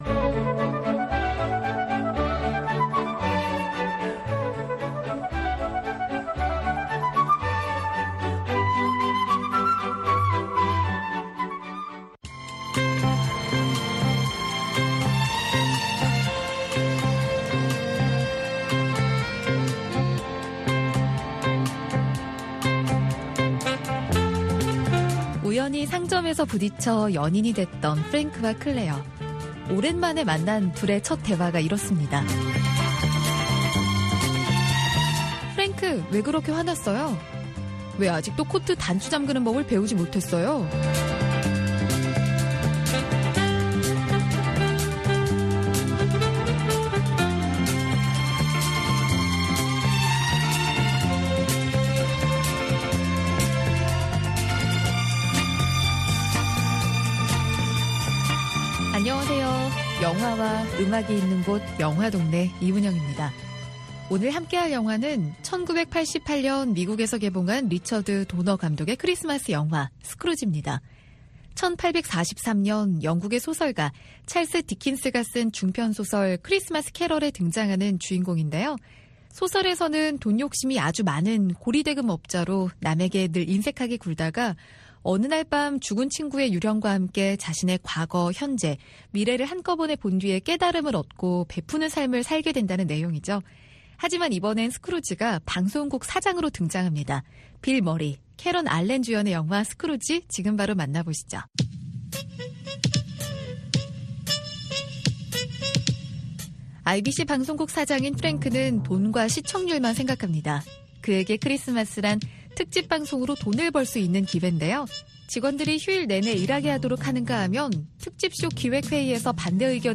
VOA 한국어 방송의 일요일 오전 프로그램 2부입니다. 한반도 시간 오전 5:00 부터 6:00 까지 방송됩니다.